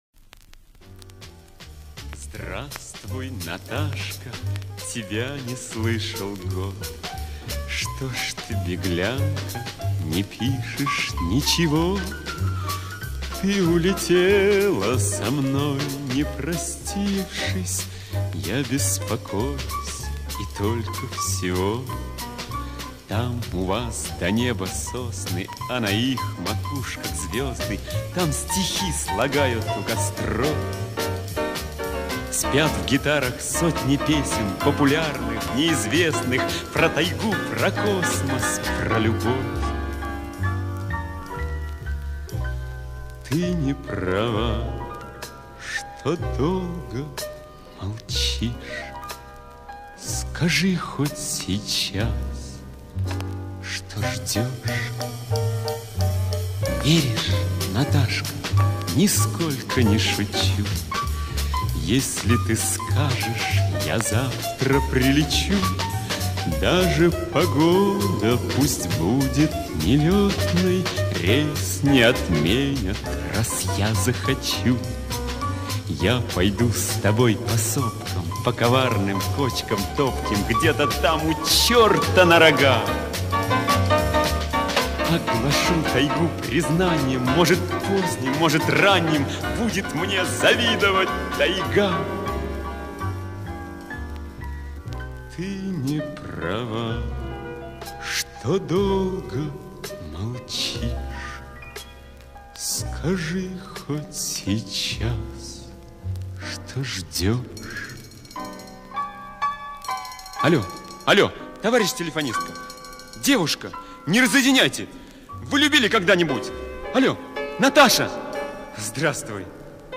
советский и российский певец (баритон).